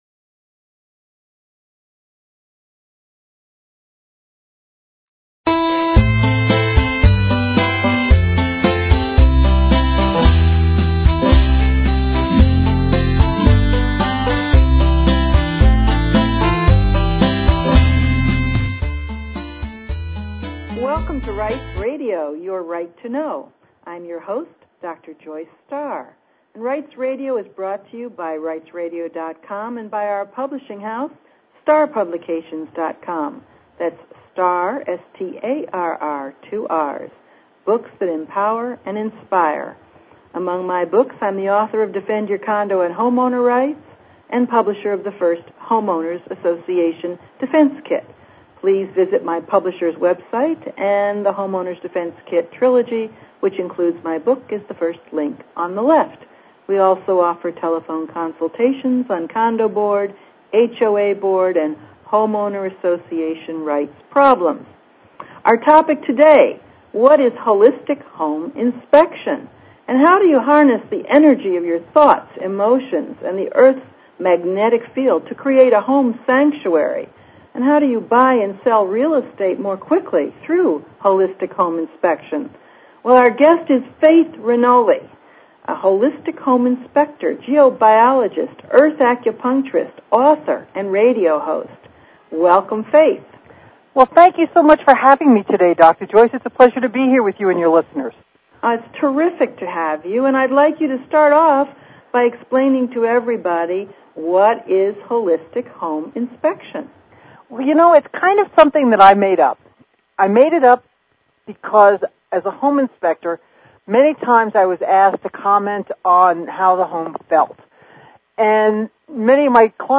Talk Show Episode, Audio Podcast, Rights_Radio and Courtesy of BBS Radio on , show guests , about , categorized as